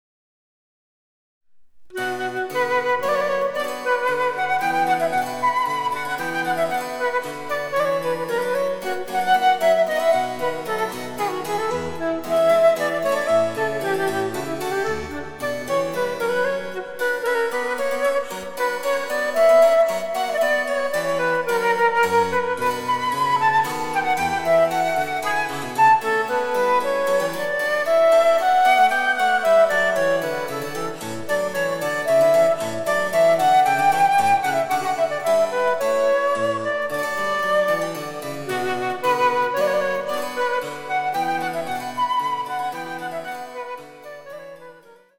■フルートによる演奏
チェンバロ（電子楽器）